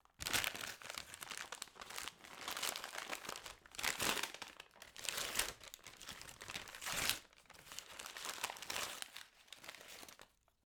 unwrap.wav